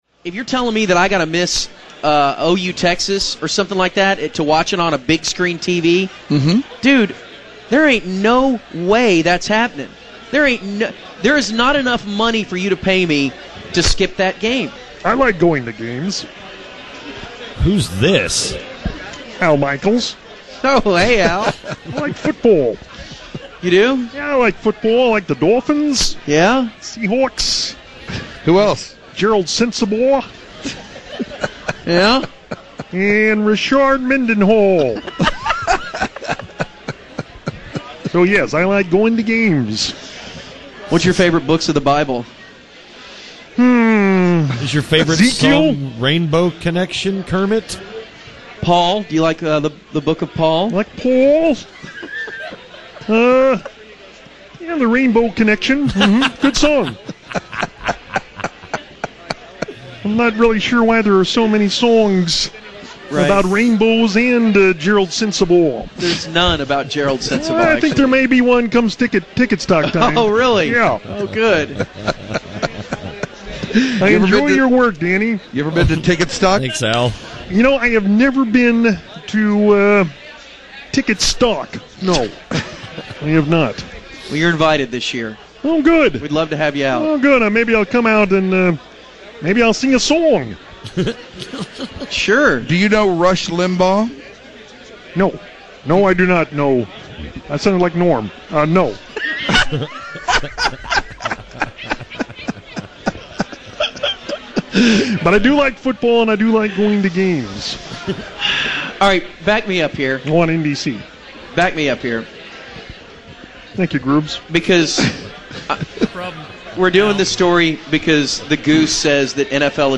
Here's as much roundtable audio as I could put together from Guys Night Out on Thursday at some place that I can't remember right now.